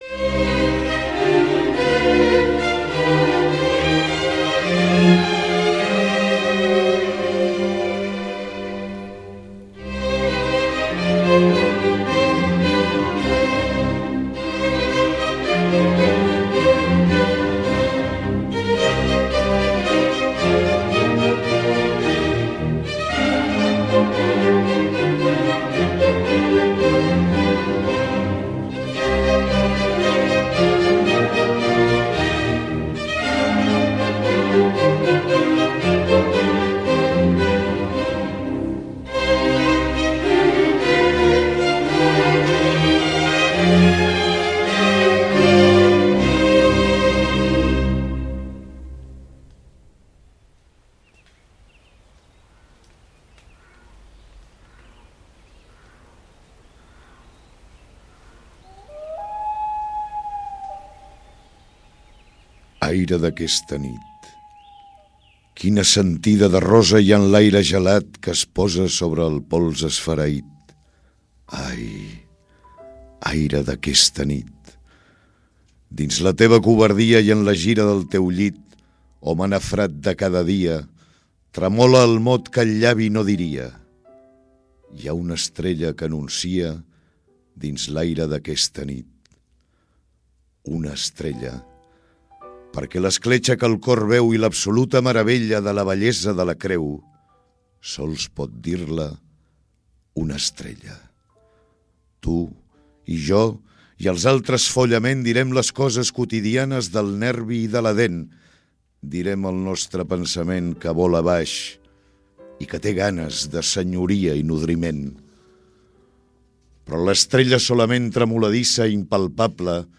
Ha esdevingut una tradició rediofònica del dia de Nadal a primera hora.
amb la realització i l’ambientació musical